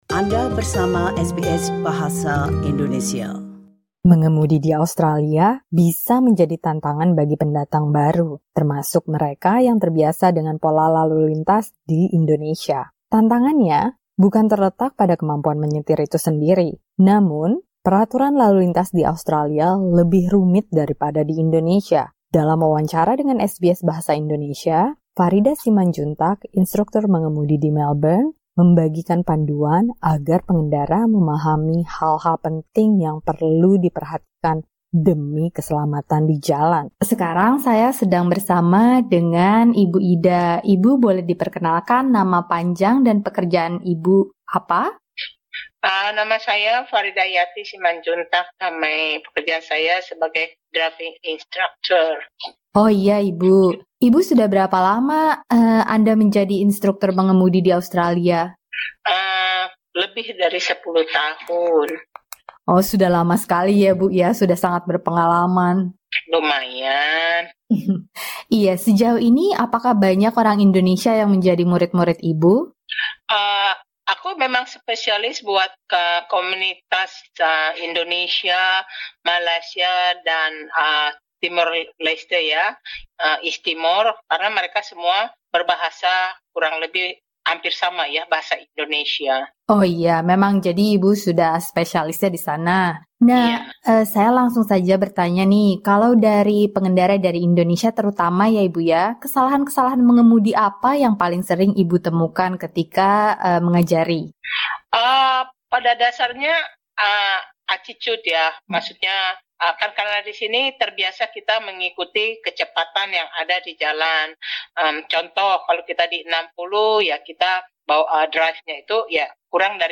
Dalam wawancara dengan SBS Bahasa Indonesia